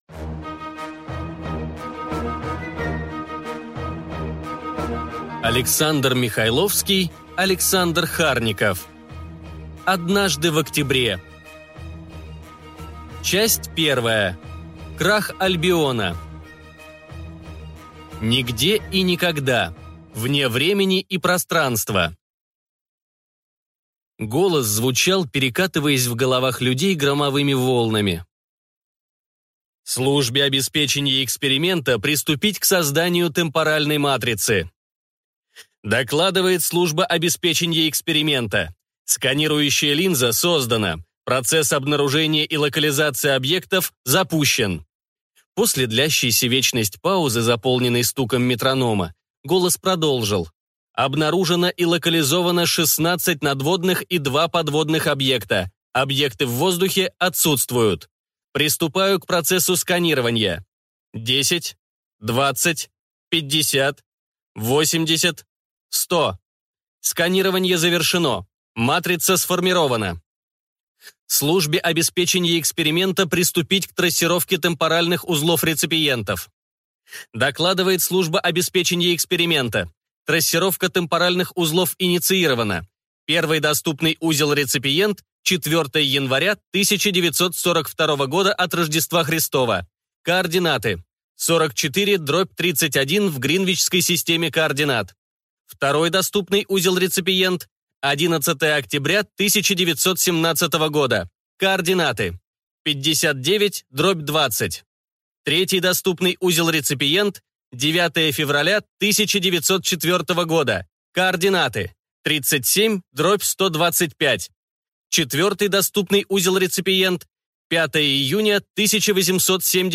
Аудиокнига Однажды в октябре | Библиотека аудиокниг
Прослушать и бесплатно скачать фрагмент аудиокниги